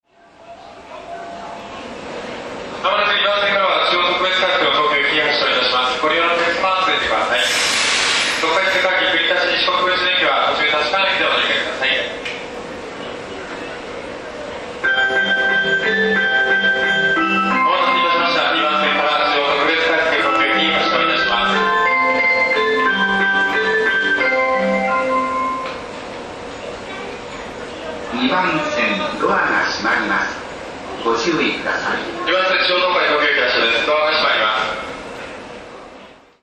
全ホーム旋律は同じですがアレンジが異なります。